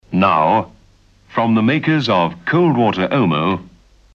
You know you're listening to a Springbok Radio programme by the distinctive voices of the announcers that can be heard at the top and tail of each episode...
There are three versions of this pre-recorded announcement heard in the surviving episodes.